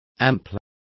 Also find out how ampolla is pronounced correctly.